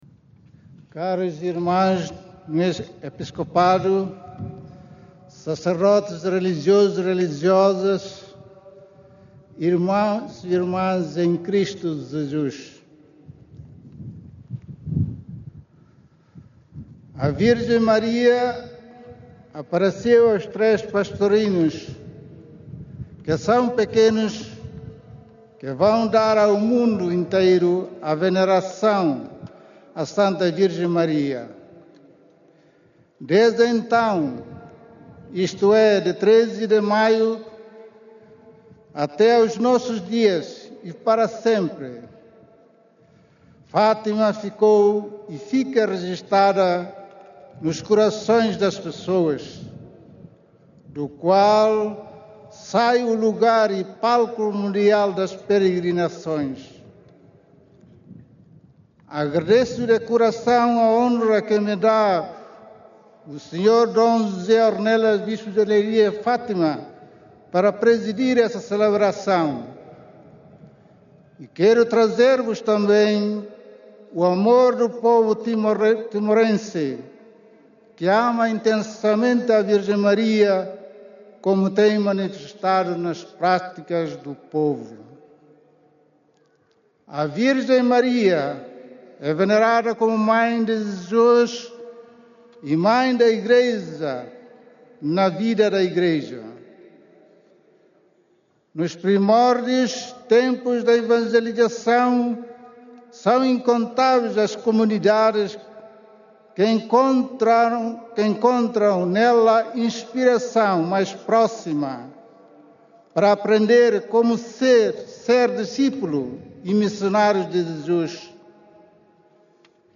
O papel da Virgem Maria na fé cristã e na vida da Igreja foi enfatizado por D. Norberto do Amaral, na homilia que proferiu esta manhã, no Recinto de Oração do Santuário de Fátima, na missa da Peregrinação Internacional Aniversária de Setembro.